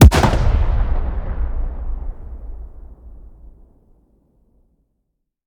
weap_rpapa7_fire_plr_atmo_04.ogg